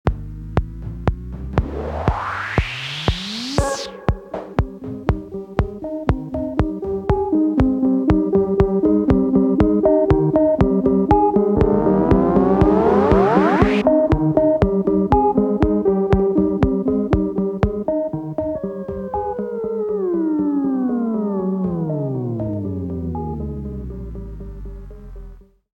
Die Herausforderung für die Start-Stipendiatinnen und Stipendiaten war es, Beethoven´s 5. Synphonie mit dem Modular Synthesizer in Form von Klingeltönen zu zitieren.